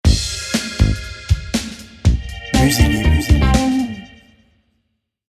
Fun, funky, retro, lumineux